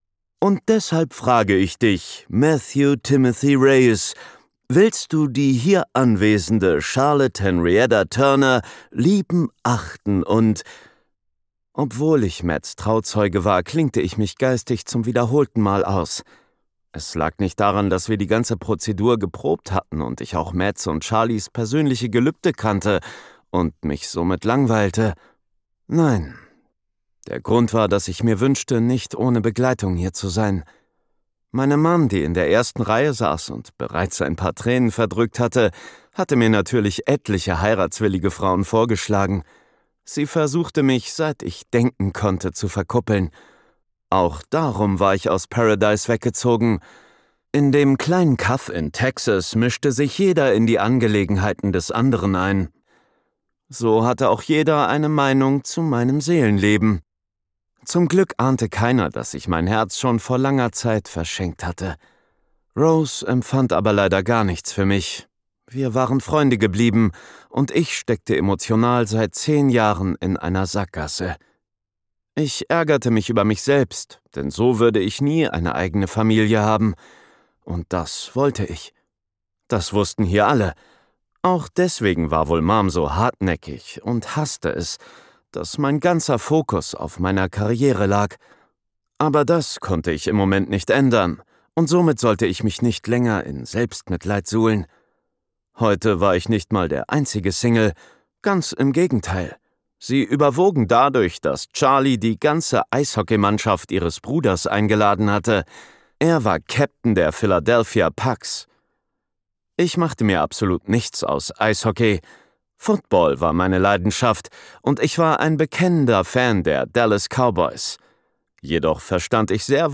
dunkel, sonor, souverän, markant, sehr variabel
Mittel minus (25-45)
Hörbuch - Romance
Audiobook (Hörbuch)